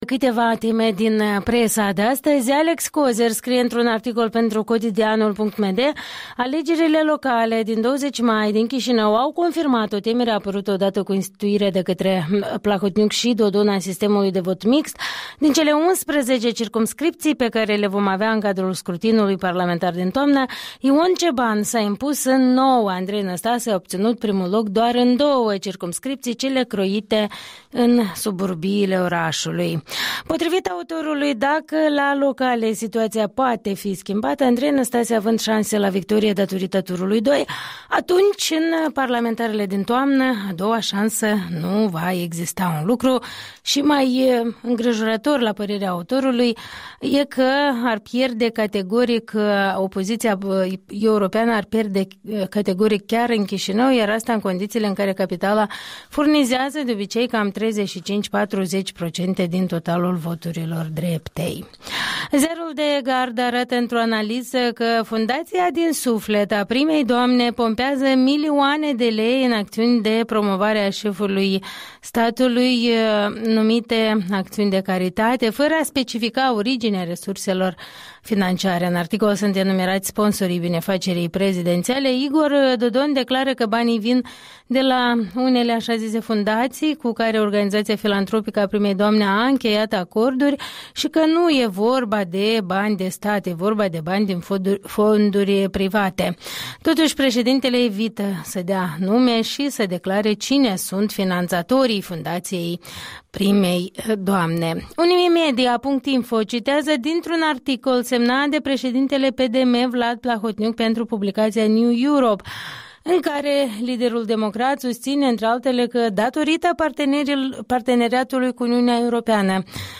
Revista presei